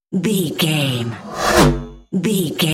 Pass by sci fi fast
Sound Effects
Fast
futuristic
pass by
car
vehicle